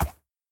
sounds / mob / horse / soft3.ogg